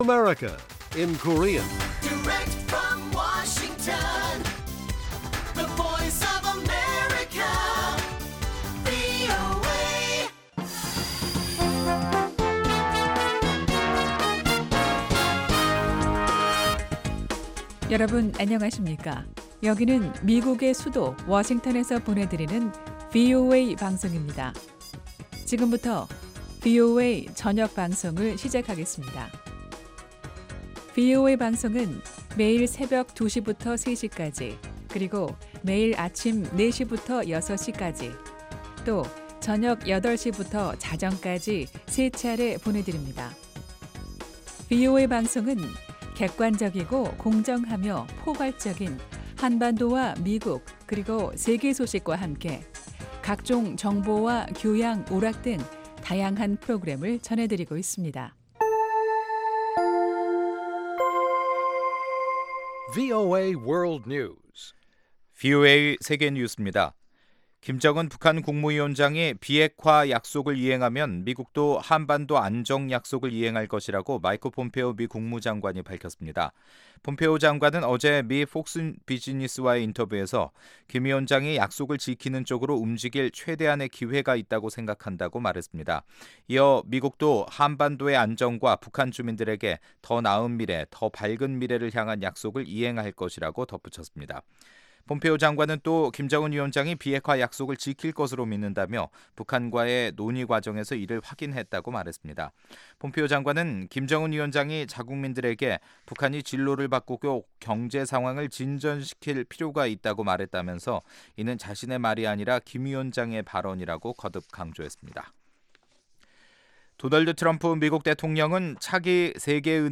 VOA 한국어 간판 뉴스 프로그램 '뉴스 투데이', 2019년 2월 7일 1부 방송입니다. 마이크 폼페오 미 국무장관은 한 언론과의 인터뷰에서 김정은 위원장의 비핵화 약속은 이행될 것으로 기대한다고 말했습니다. 트럼프 대통령의 올해 국정연설은 지난 1년 사이 대북 정책기조가 얼마나 달라졌는지를 보여주는 것이었다고 미국의 한반도 전문가들이 평했습니다.